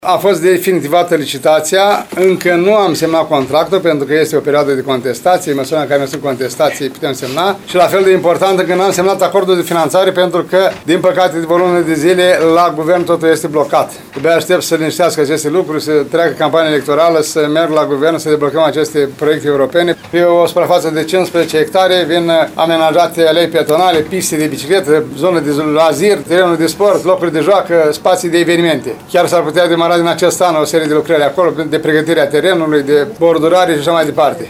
Primarul ION LUNGU a declarat astăzi că valoarea contractului este de 12 milioane 800 mii lei, finanțarea urmând să fie asigurată din fonduri europene.